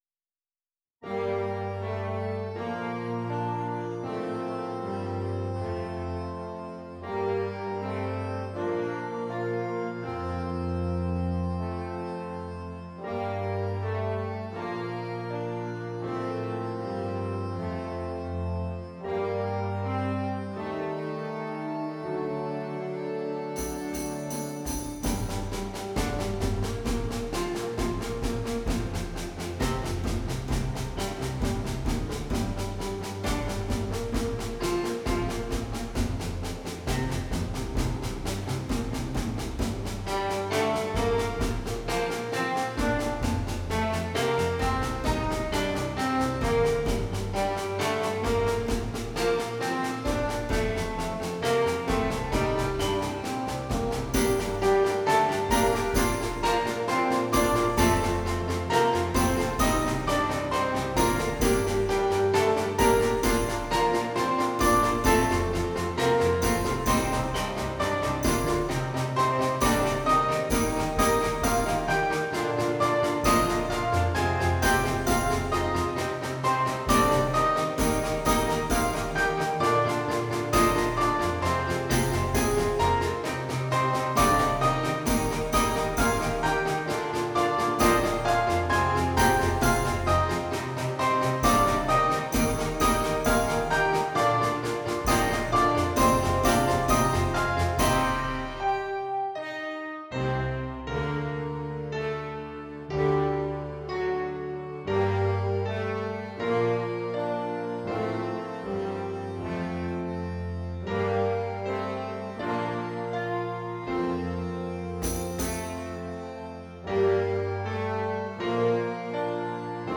Orchestra scolastica